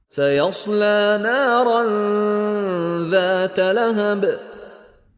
Contoh Bacaan dari Sheikh Mishary Rashid Al-Afasy
DISEMBUNYIKAN/SAMARKAN sebutan huruf Nun Sakinah/Tanwin tidak Selari beserta dengung 2 harakat.